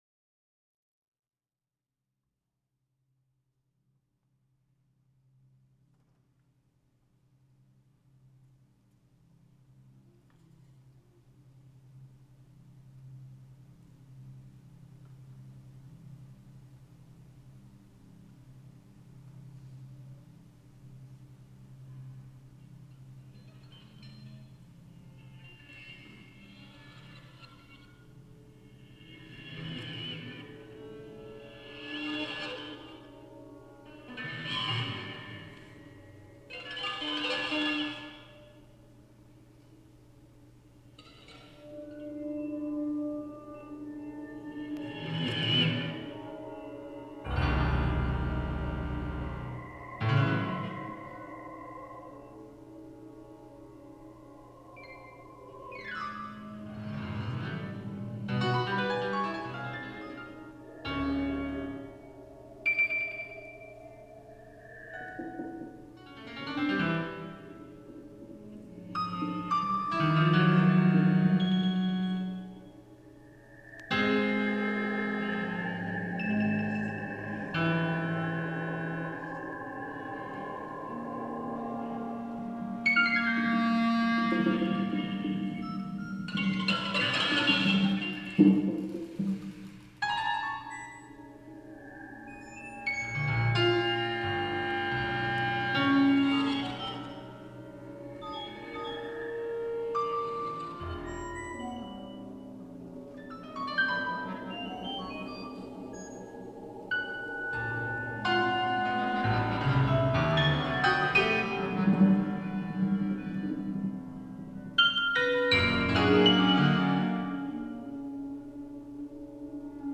clarinet
guitar
organ
percussion
Lexikon-Sonate ** * acoustically enhanced